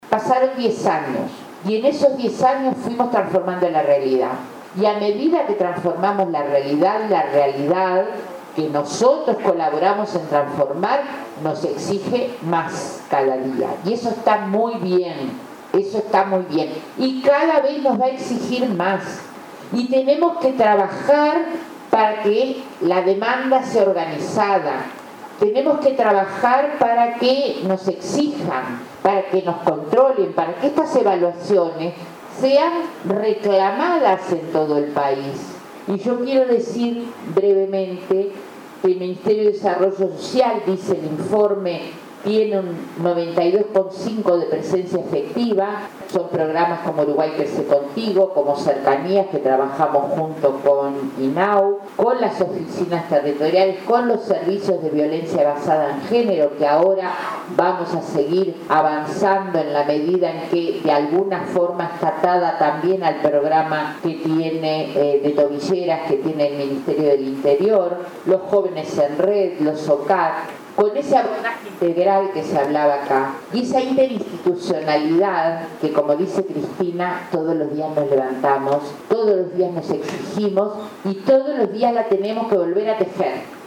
El Estado aplica desde hace 10 años políticas específicas de protección a la infancia y adolescencia mediante instituciones y programas. “Hubo que romper con estructuras y crear conciencia de interinstitucionalidad”, afirmó la titular del Mides, Marina Arismendi, en el aniversario del Sistema Integrado de Protección a la Infancia y Adolescencia contra la Violencia.